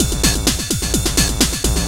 DS 128-BPM B6.wav